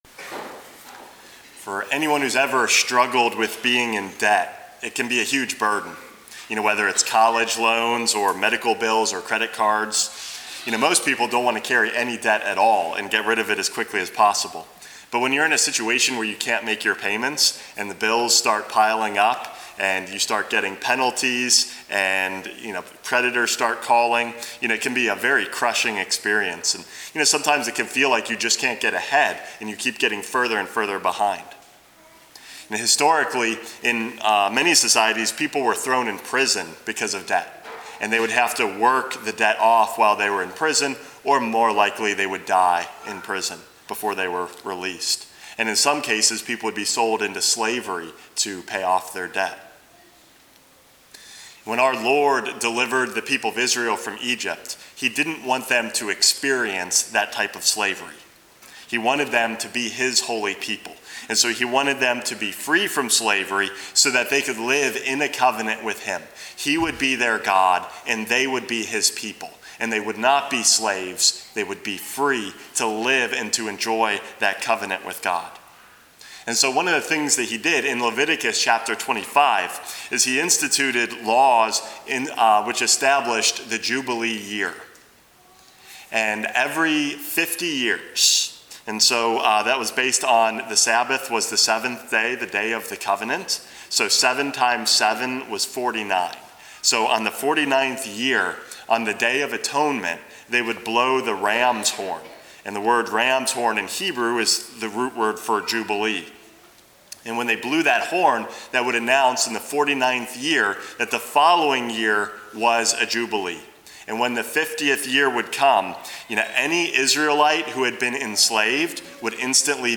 Homily #436 - A Year Acceptable to the Lord